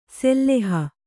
♪ selleha